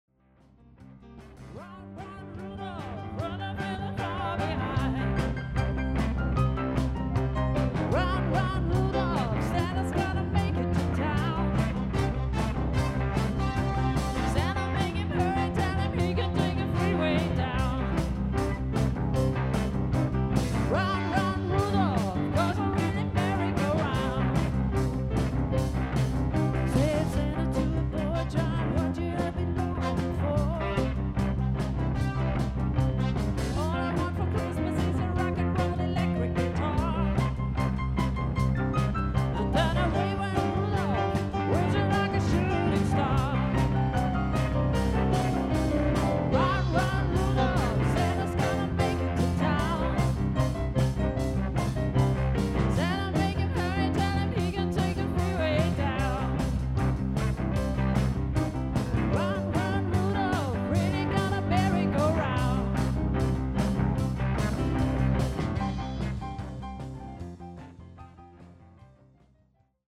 Auf dieser Seite findet Ihr Hörproben unserer Auftritte.